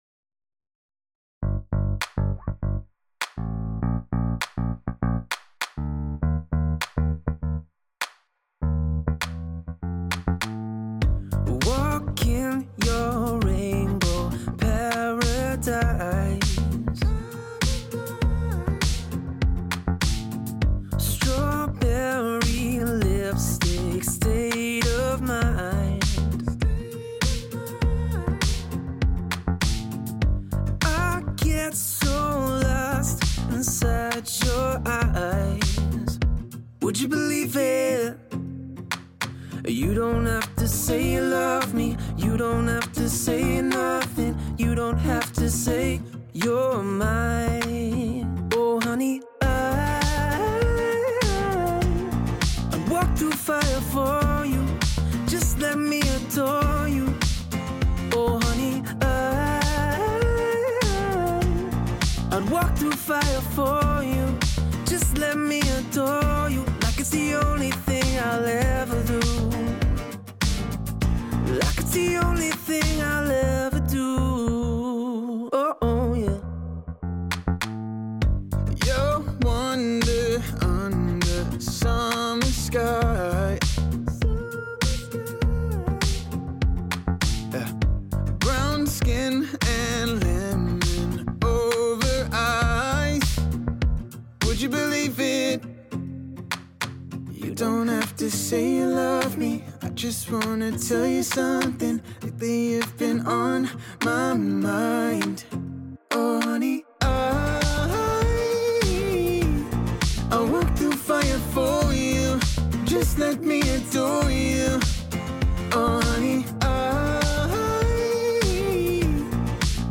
Dual Vocals | Dual | Guitars | Looping | DJ